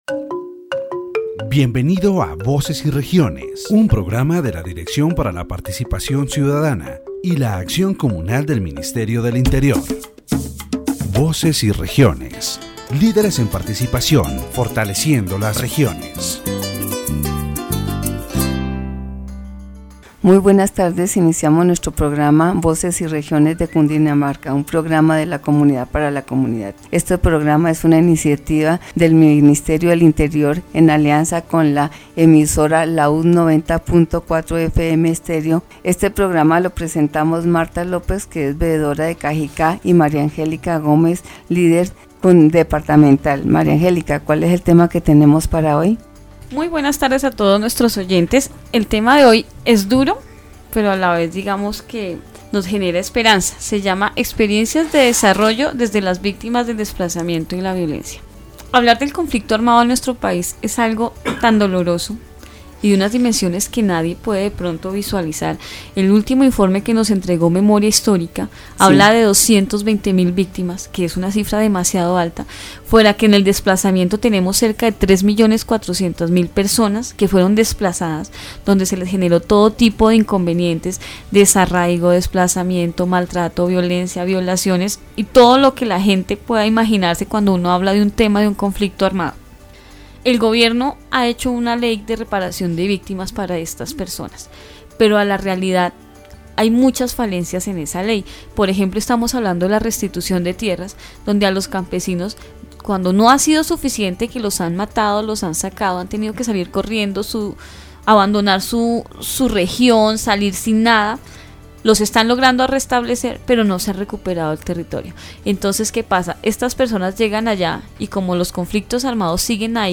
The radio program "Voices and Regions" of the Directorate for Citizen Participation and Communal Action of the Ministry of the Interior focuses on the development experiences of victims of displacement and violence in Colombia.